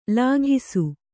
♪ lāghisu